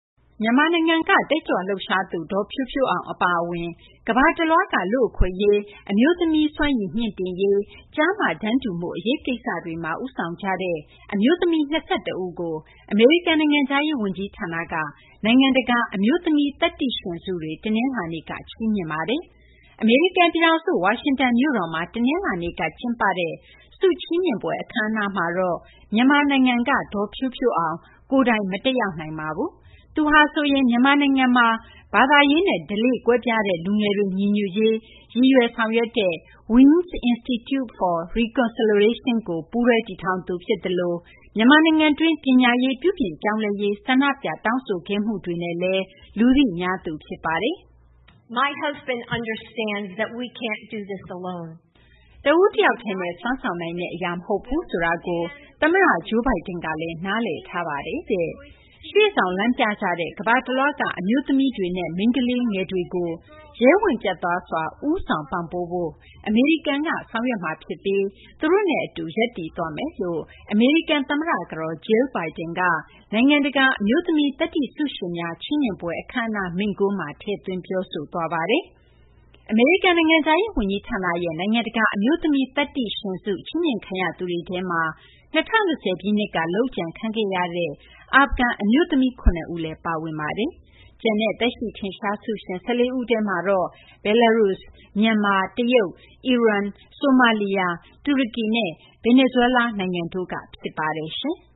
အမျိုးသမီးသတ္တိရှင်ဆုချီးမြှင့်ပွဲ ကန်သမ္မတကတော် တက်ရောက်
“တဦးတယောက်တည်းနဲ့ စွမ်းဆောင်နိုင်တဲ့ အရာ မဟုတ်ဘူးဆိုတာကို ခင်ပွန်းဖြစ်သူ သမ္မတ Joe Biden က နားလည်ထားပါတယ်တဲ့။ ရှေ့ဆောင် လမ်းပြကြတဲ့ ကမ္ဘာတလွှားက အမျိုးသမီးတွေနဲ့ မိန်းကလေးငယ်တွေကို ရဲဝံ့ ပြတ်သားစွာ ဦးဆောင် ပံ့ပိုးဖို့ အမေရိကန်တွေက ဆောင်ရွက်မှာ ဖြစ်ပြီး သူတို့နဲ့ အတူ ရပ်တည်သွားမယ်” လို့ သမ္မတ ကတော် Jill Biden နိုင်ငံတကာ အမျိုးသမီး သတ္တိဆုရှင်များ ချီးမြှင့်ပွဲ အခမ်းအနားမိန့်ခွန်းမှာ ထည့်သွင်း ပြောဆိုခဲ့ပါတယ်။